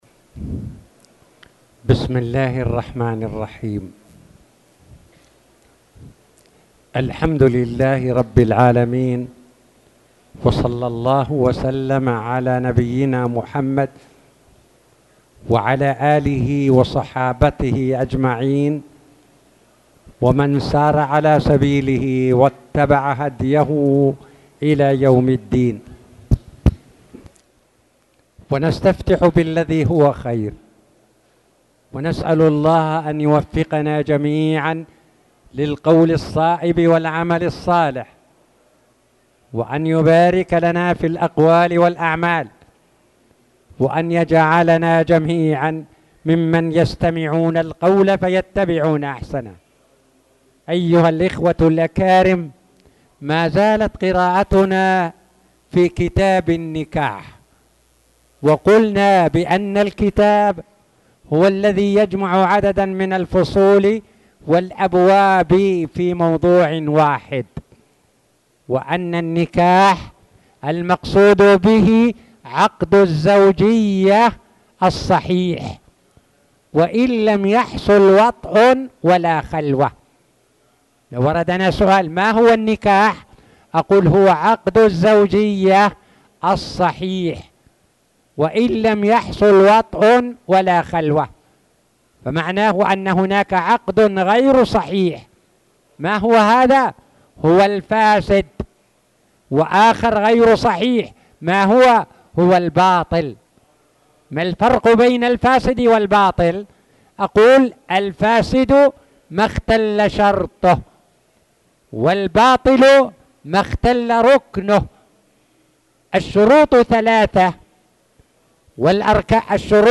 تاريخ النشر ١٩ ربيع الأول ١٤٣٨ هـ المكان: المسجد الحرام الشيخ